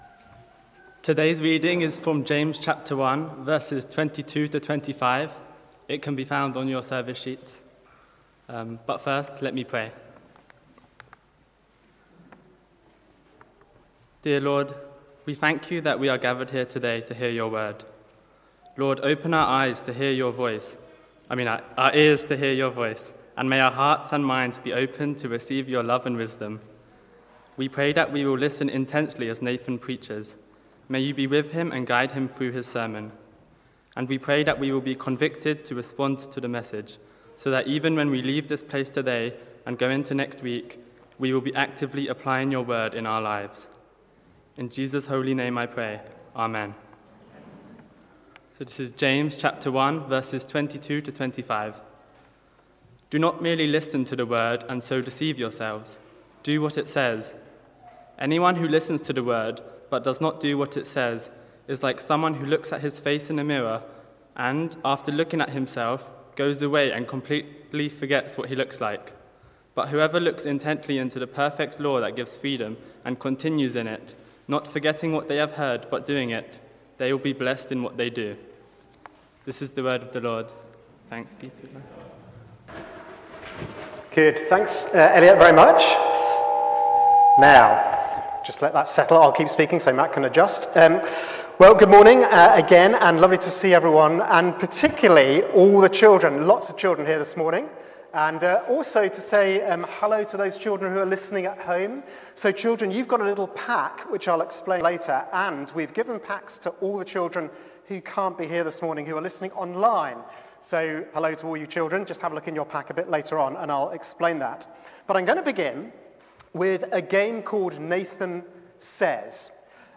James 1:22-25 – All Age Service – Listen & Act